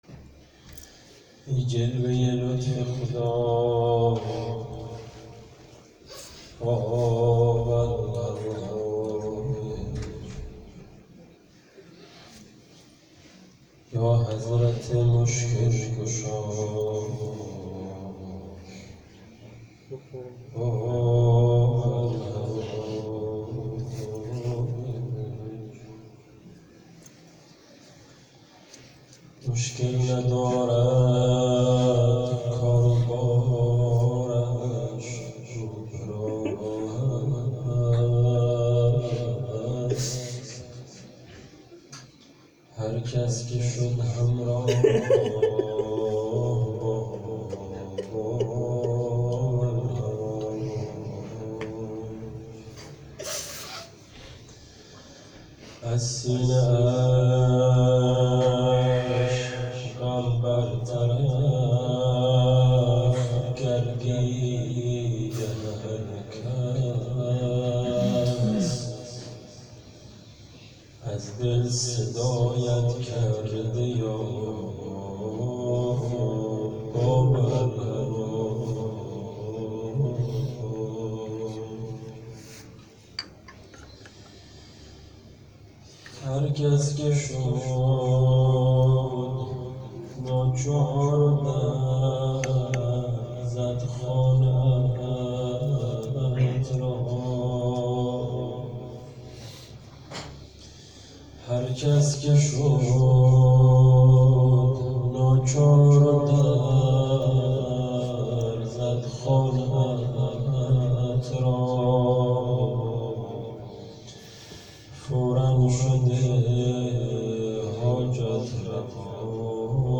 خیمه گاه - هیئت محبان المهدی(عج)آمل - شب شهادت امام موسی کاظم(ع)_بخش سوم روضه